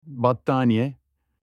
blanket-in-turkish.mp3